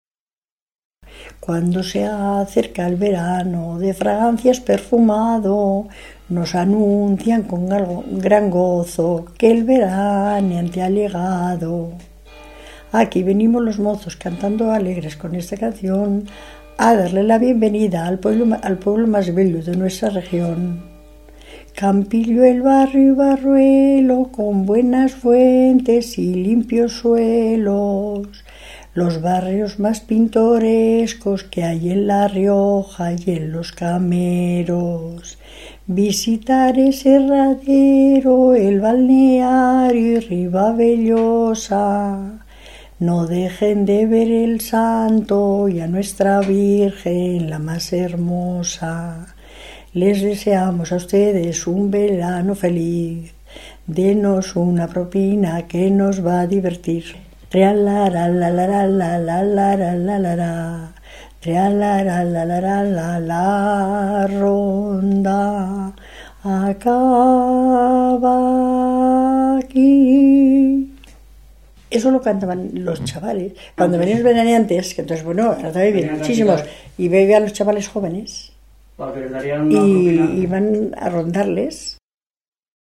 Clasificación: Cancionero
Lugar y fecha de recopilación: Torrecilla de Cameros, 9 de febrero de 2000